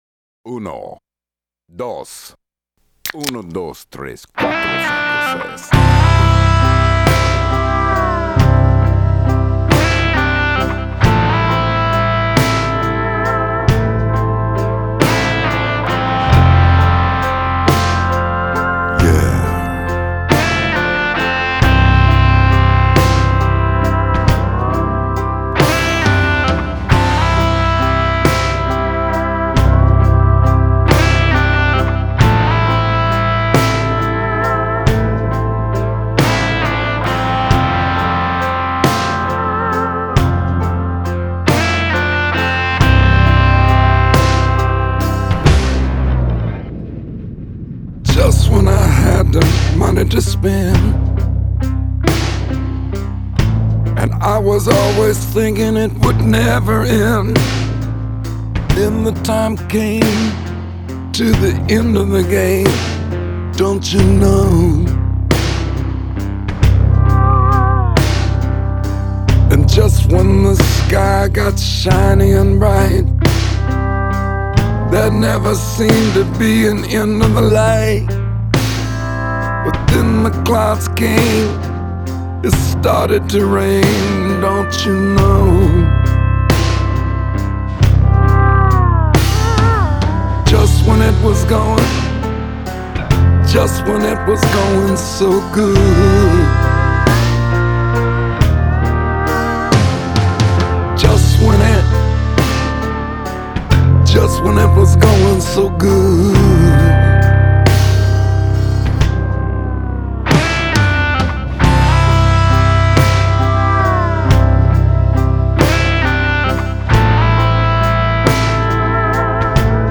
я ставлю медляк.
рок-музыка